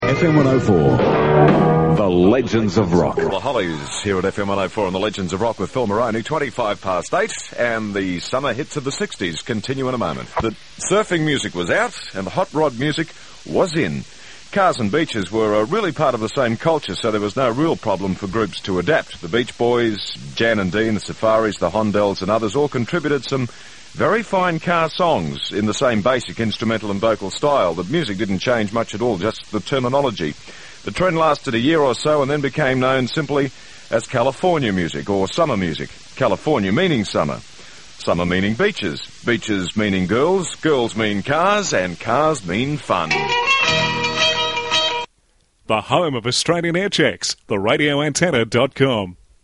RA Aircheck – FM 104